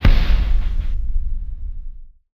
Big Drum Hit 34.wav